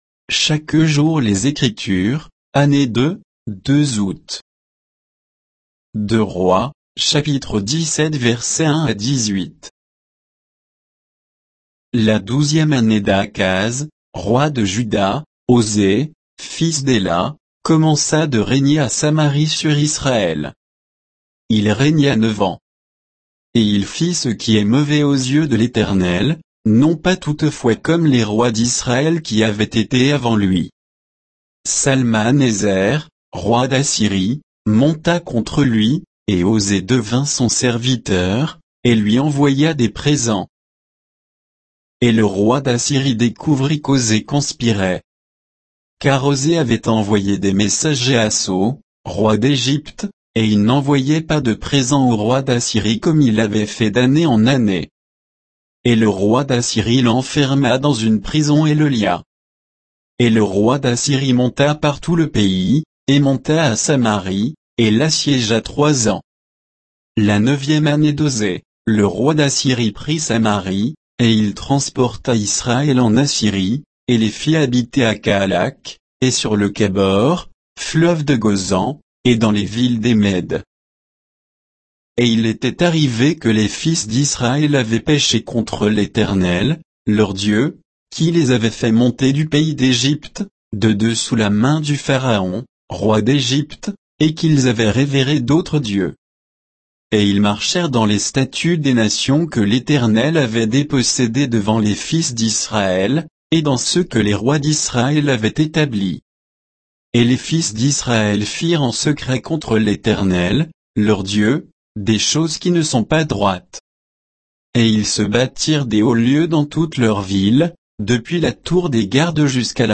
Méditation quoditienne de Chaque jour les Écritures sur 2 Rois 17, 1 à 18